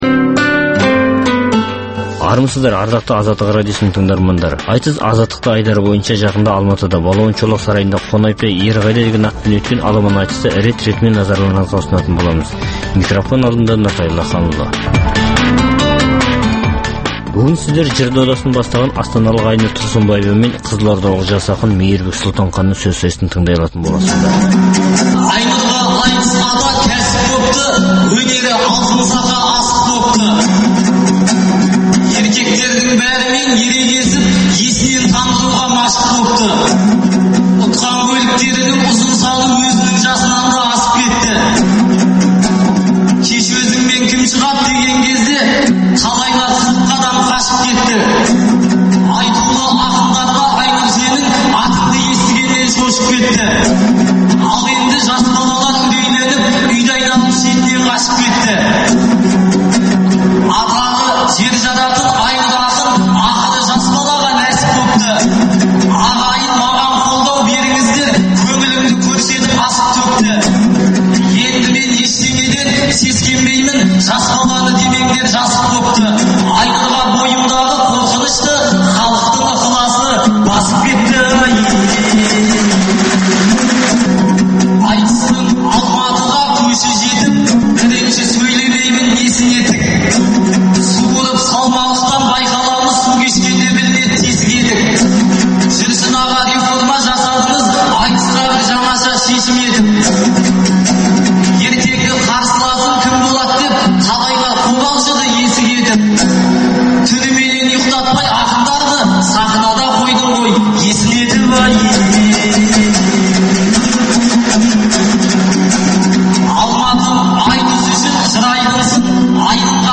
Айтыс - Азаттықта